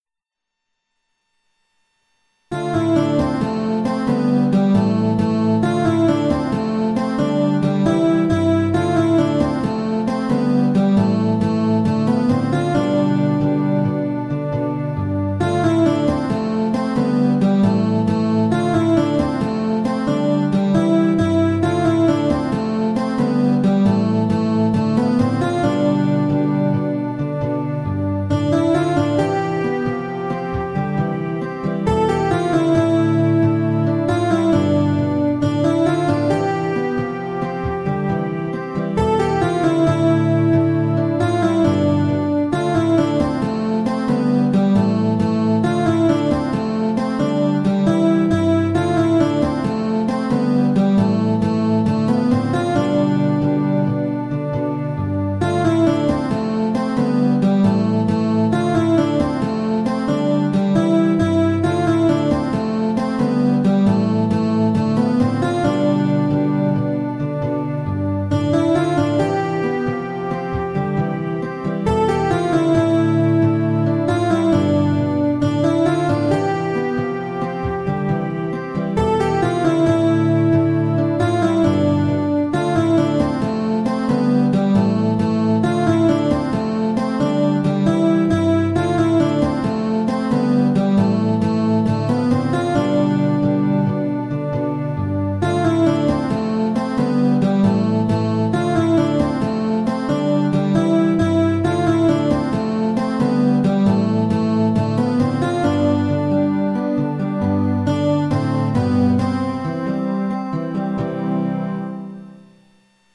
An energetic praise of the Creator God.